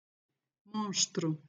(pronunciação)